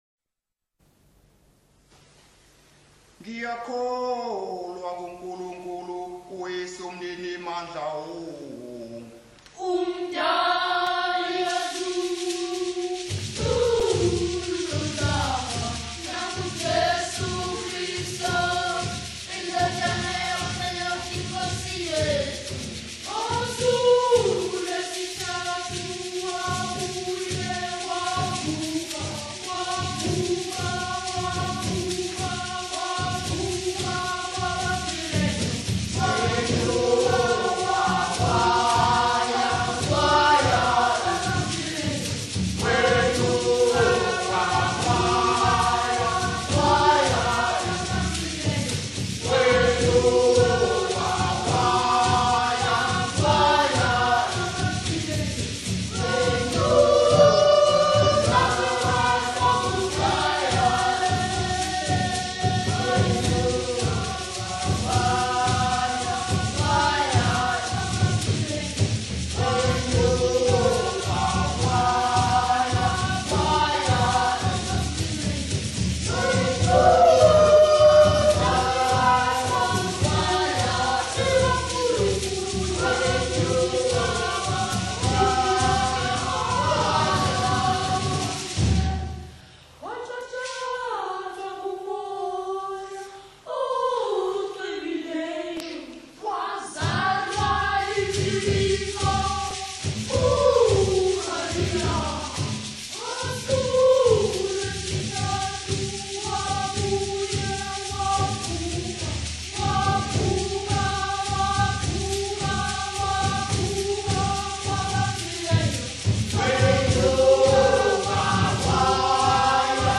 choral songs for Catholic Mass. In his compositions, he combines the text of the Catholic liturgy with indigenous Zulu musical styles.